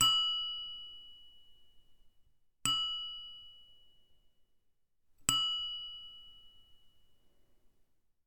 Faint Ding Sound OWI
bell chime clang ding faint faint-bell faint-ding faint-ring sound effect free sound royalty free Sound Effects